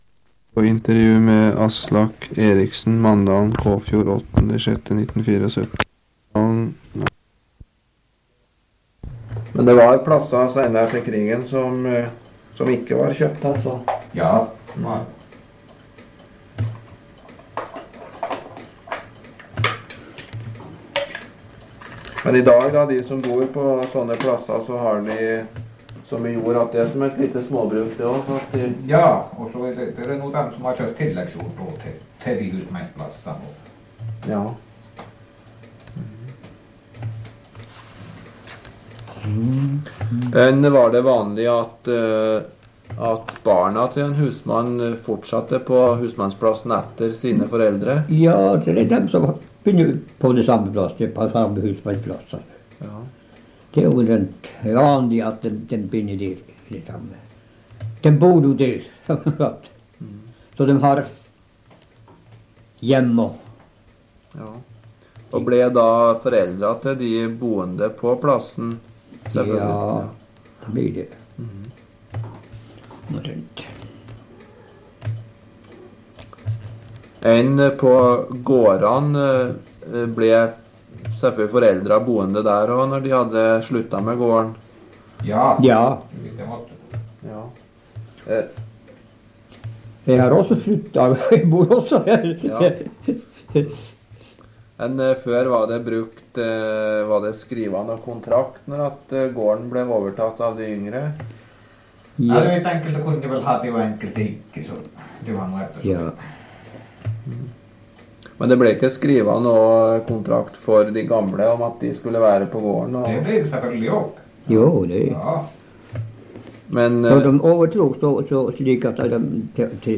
Lydopptak
Sted: Kåfjord, Manndalen